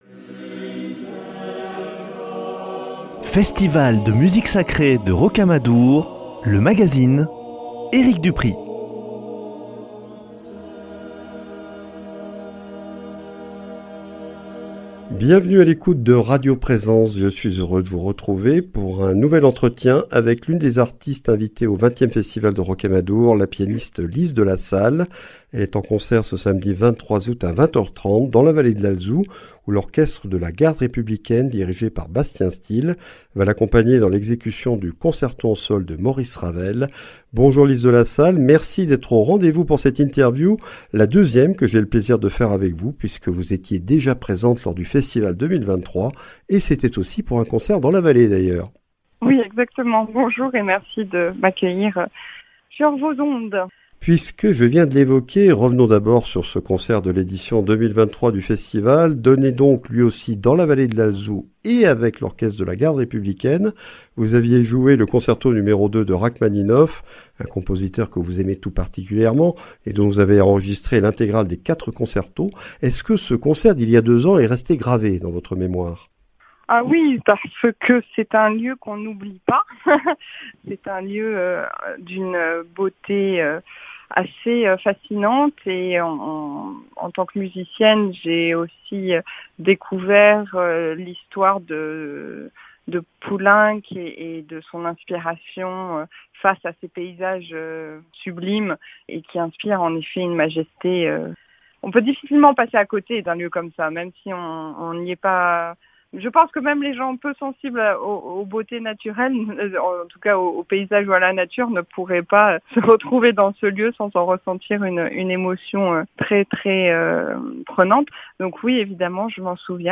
Festival de Rocamadour 2025 : ITW de Lise de la Salle